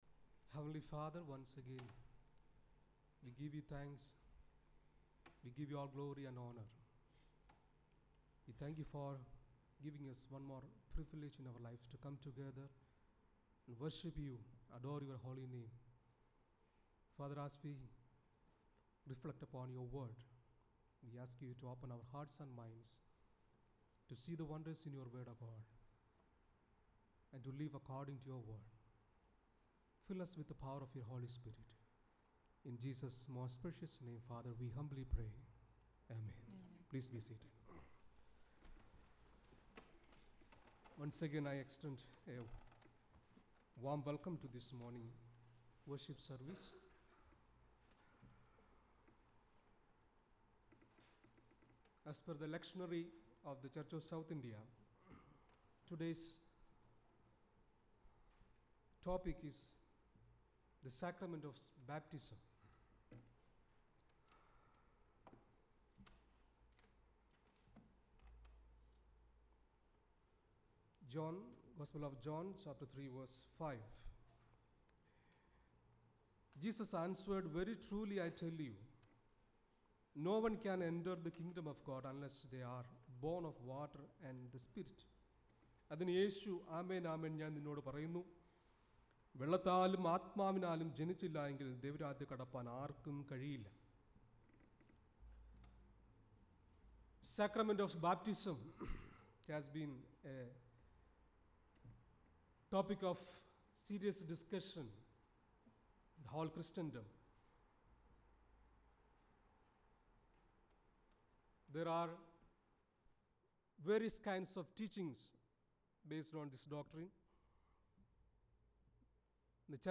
Weekly Sermon Podcast